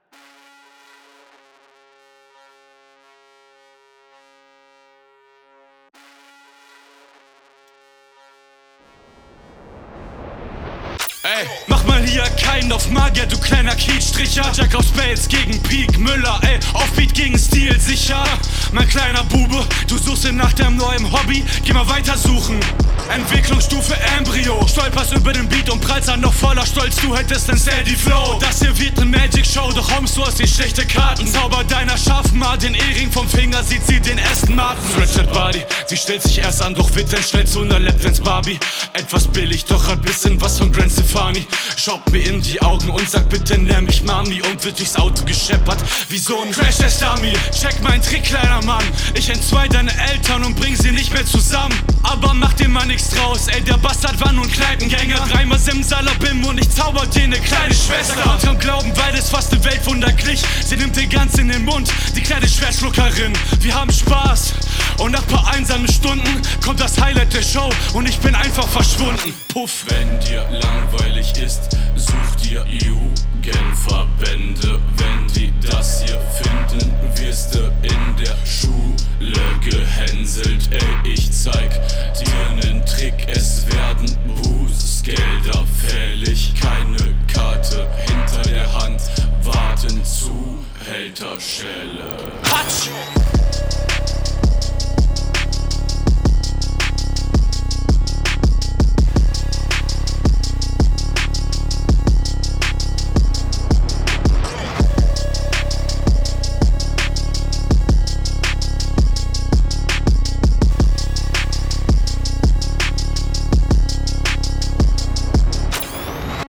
Fetter Sound und funny Lines